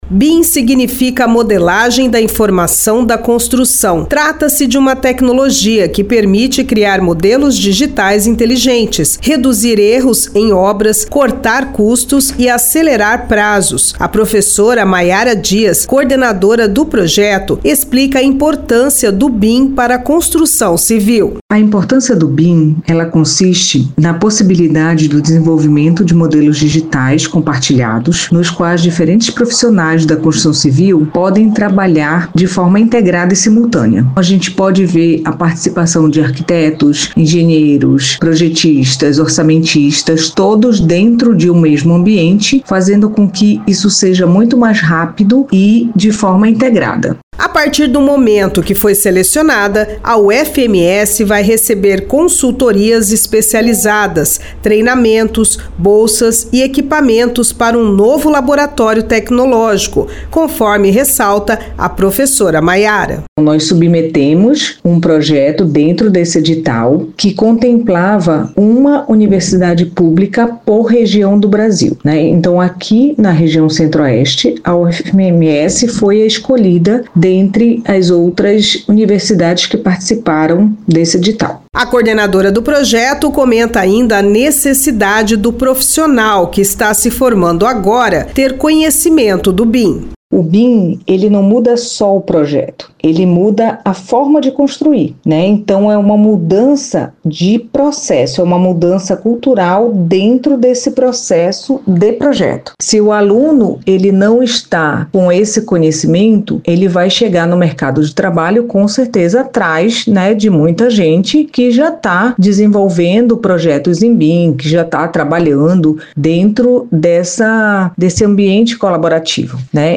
CELULA-BIM-UFMS.mp3